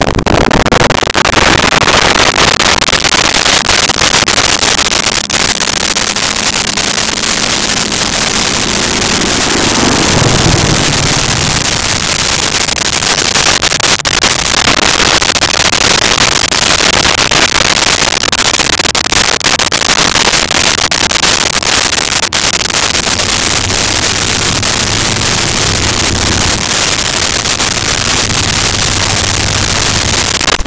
Muscle Car In Turn Idle Off
Muscle Car In Turn Idle Off.wav